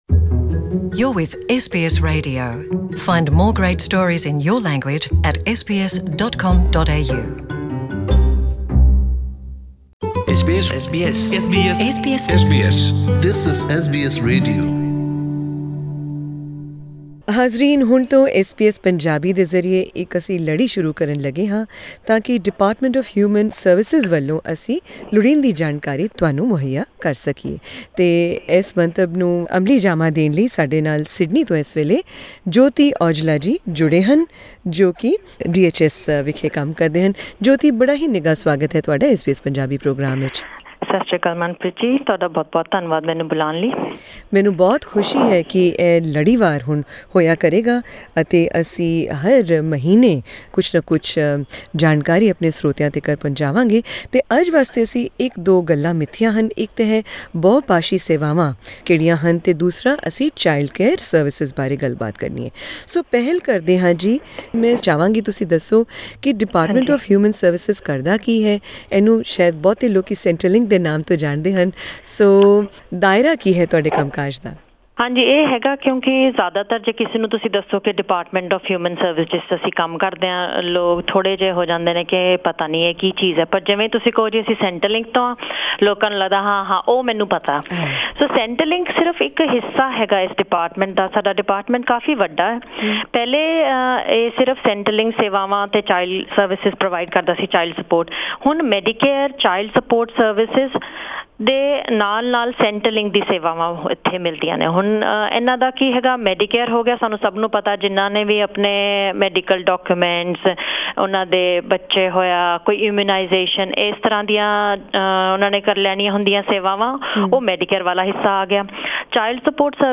(To hear the full interview in Punjabi, click on the audio link above).